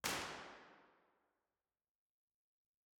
impulseresponseheslingtonchurch-007.wav